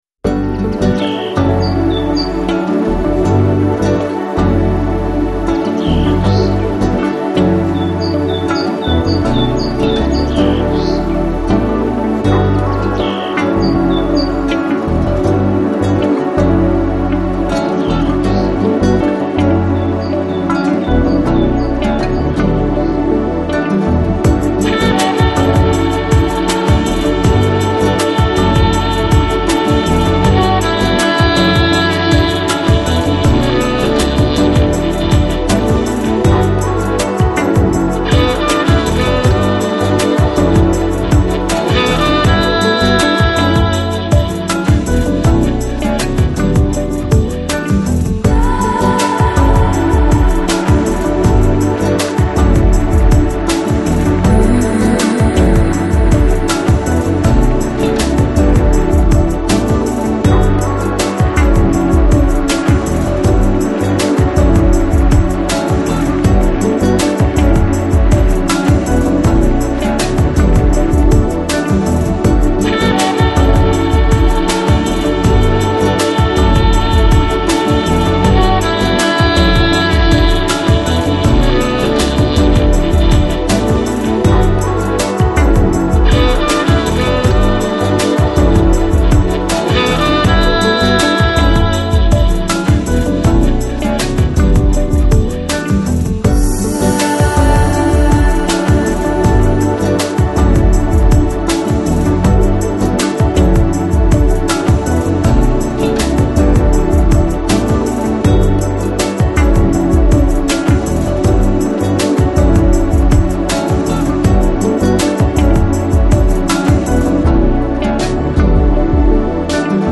Жанр: Downtempo | Lounge | Chillout